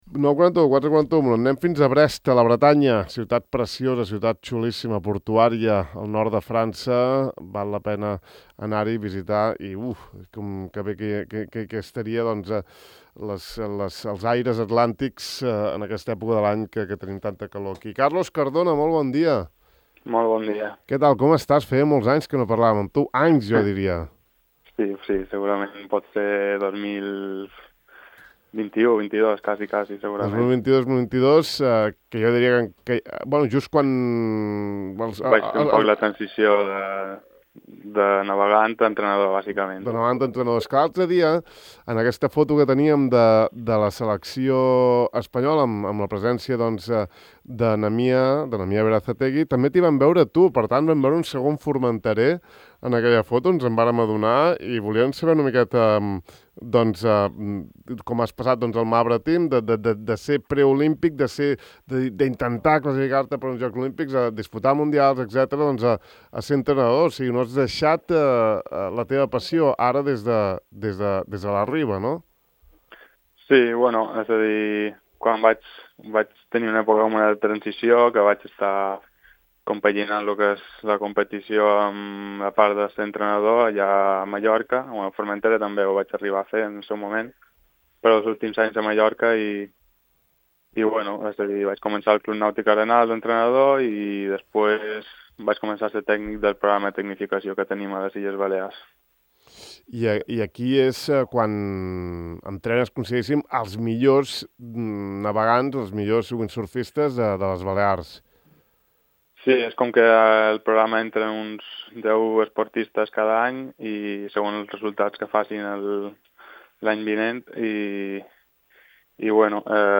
Hem conversat una estona amb ell, que ja xerra amb accent mallorquí: